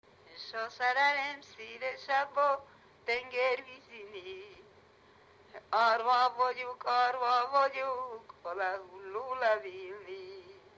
Erdély - Csík vm. - Rakottyástelep (Gyimesbükk)
Műfaj: Keserves
Stílus: 3. Pszalmodizáló stílusú dallamok
Kadencia: 5 (b3) 1 1